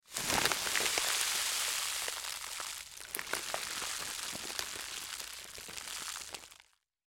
На этой странице собраны разнообразные звуки, связанные с пищевой солью: хруст кристаллов, шум пересыпания, звук растворения в воде и другие.
Шорох пакета с солью в ладони